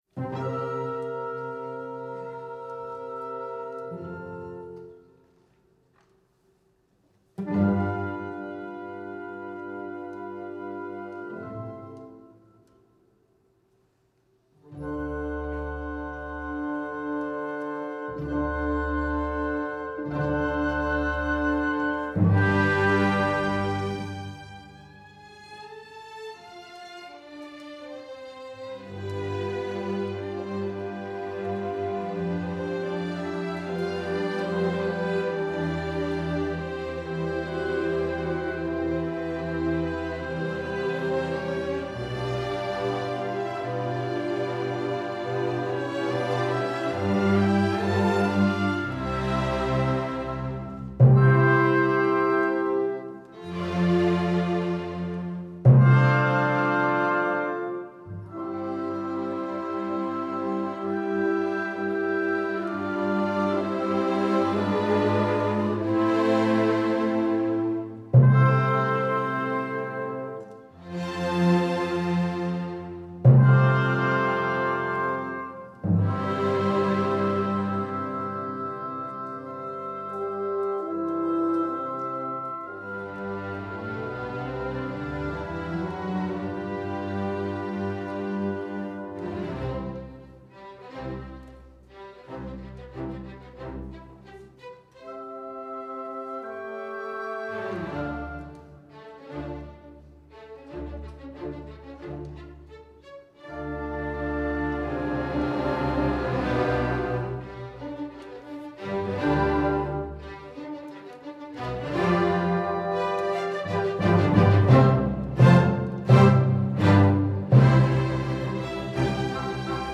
We are professional orchestra between 35 and 55 musicians, performing four separate classical music concerts throughout the year, November, December, February and May, at Iona University in New Rochelle, Westchester, New York.
• Beethoven Symphony no. 1, op. 21 in C Major - Mov't - recorded Nov., 7th, 2015 at Iona University,
1st Movt